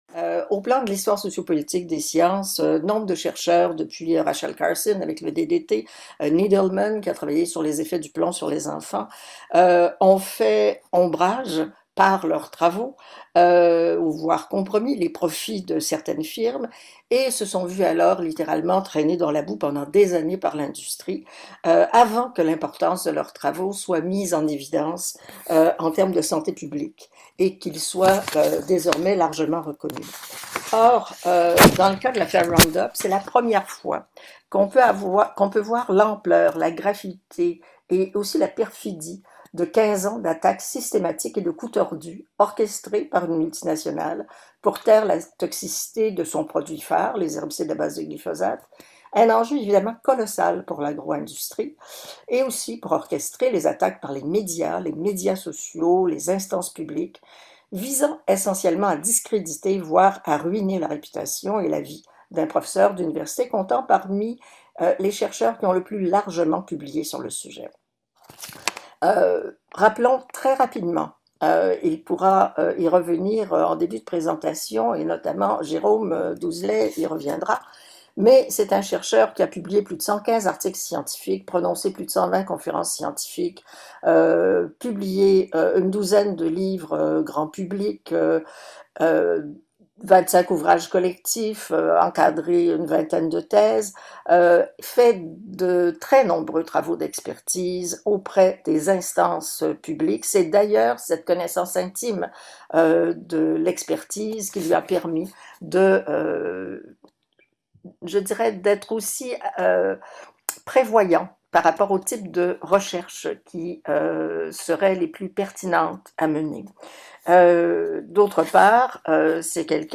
Wébinaire international